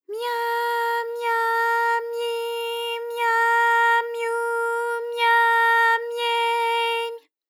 ALYS-DB-001-JPN - First Japanese UTAU vocal library of ALYS.
mya_mya_myi_mya_myu_mya_mye_my.wav